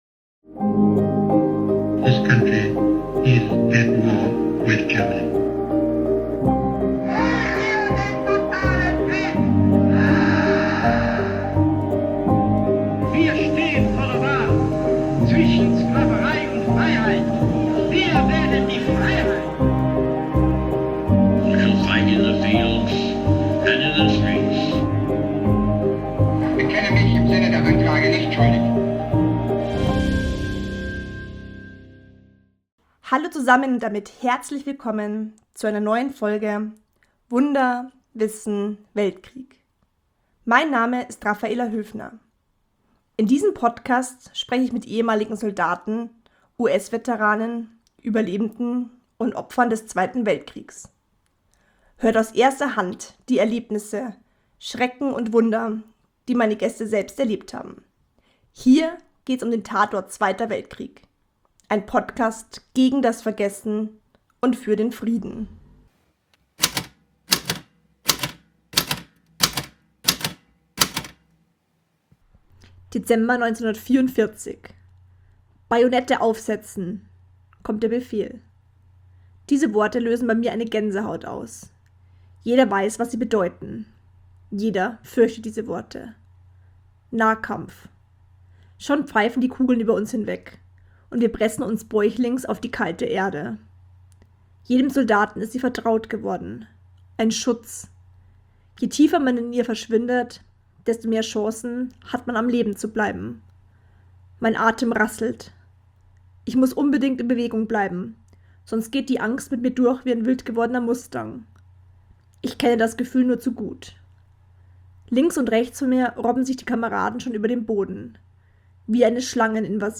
Interviewgast in dieser Folge: Dita Kraus
Beginn des Interviews ab 8 Minuten, 31 Sekunden